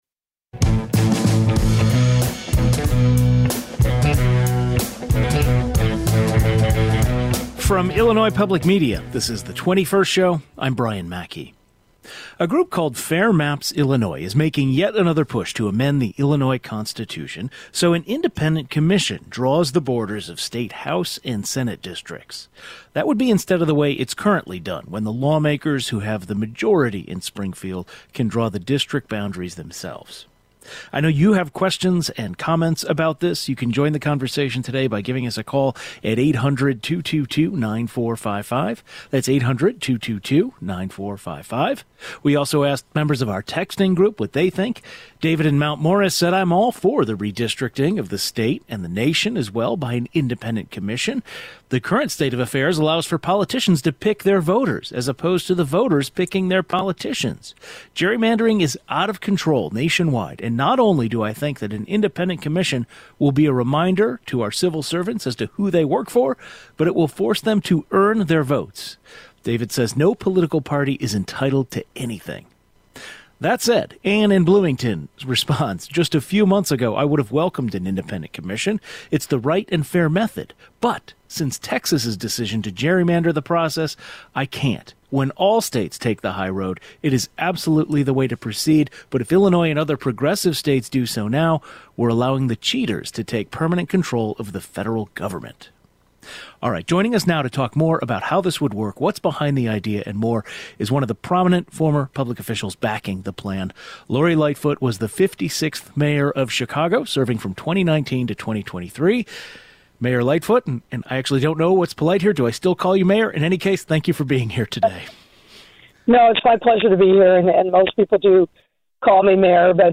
The 21st Show is Illinois' statewide weekday public radio talk show, connecting Illinois and bringing you the news, culture, and stories that matter to the 21st state.
The former mayor of Chicago, Lori Lightfoot, joins the prgram to talk more about how this would work, what’s behind the idea, and more.